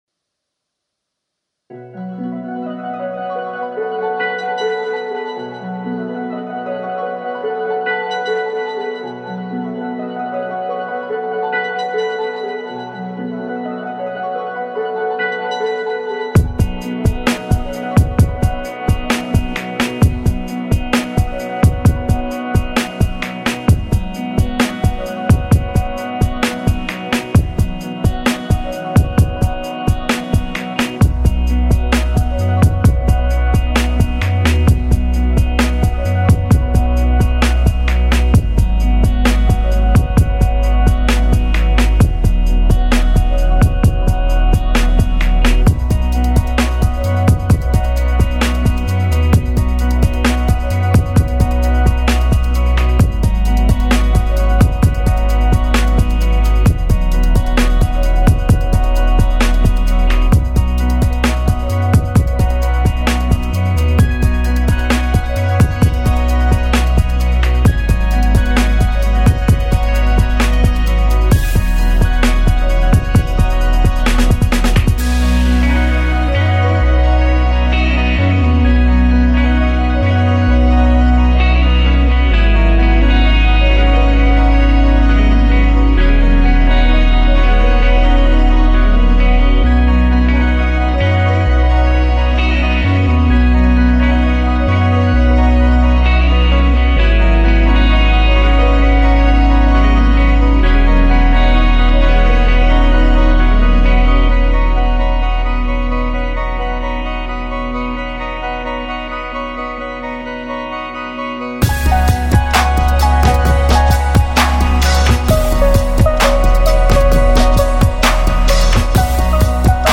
ambient/electronic duo
keyboardists/composers